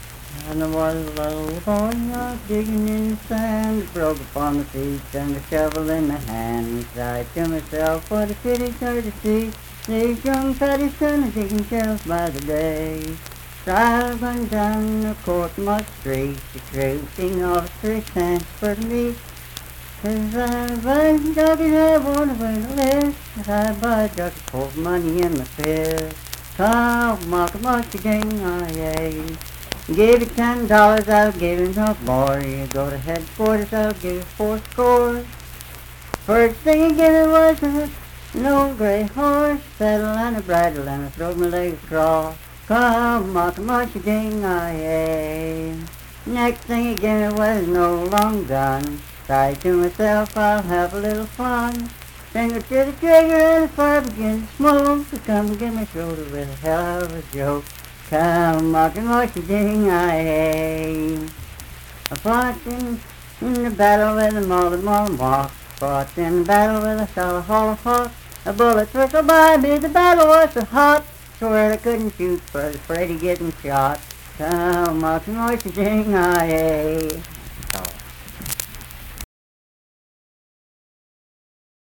Unaccompanied vocal music performance
Verse-refrain 5(8-9w/R).
Voice (sung)